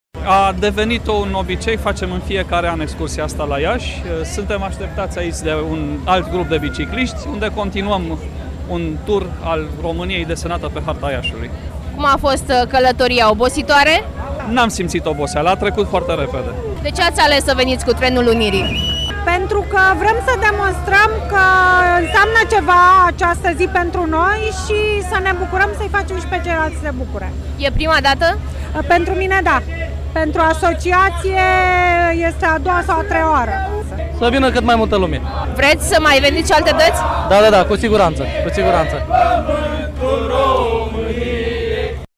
La destinaţie, ei au marcat Ziua Unirii cu o horă, după care au pedalat pe stăzile oraşului pentru a schiţa harta României:
24-ian-ora-14-vox-biciclisti.mp3